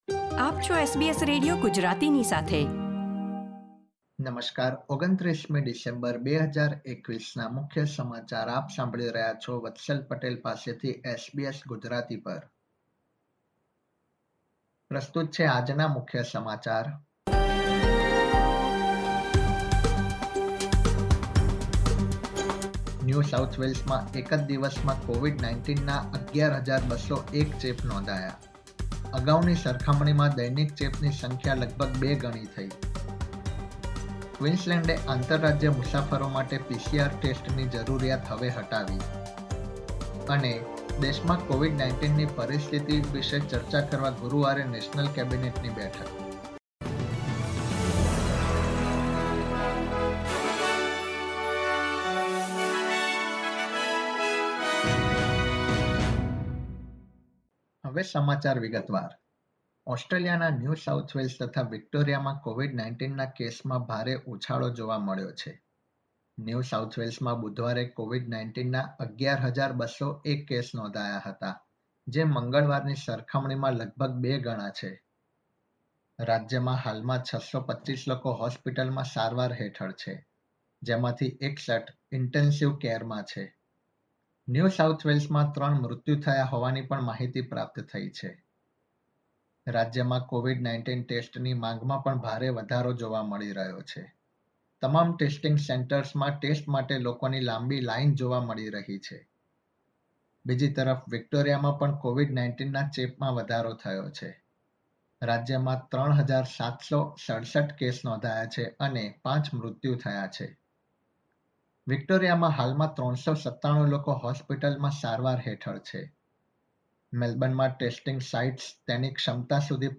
SBS Gujarati News Bulletin 29 December 2021